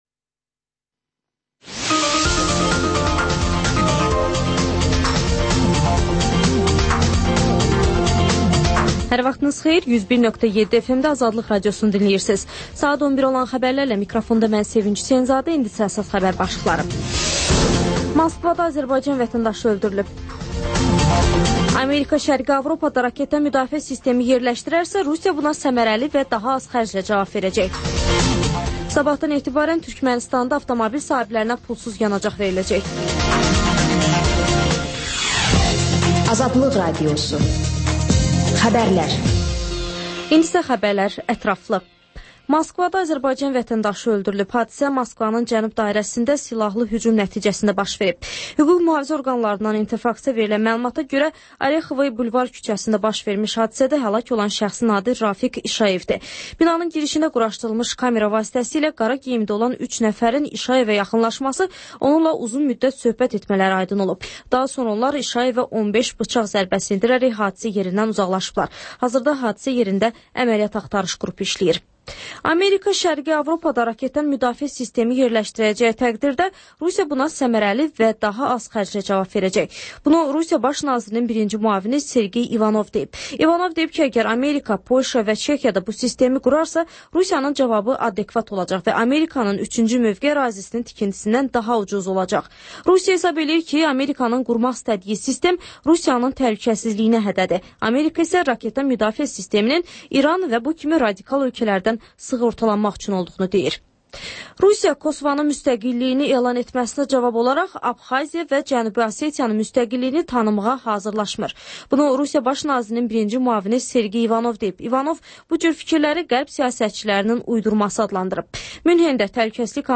Xəbərlər, İZ: Mədəniyyət proqramı və TANINMIŞLAR verilişi: Ölkənin tanınmış simalarıyla söhbət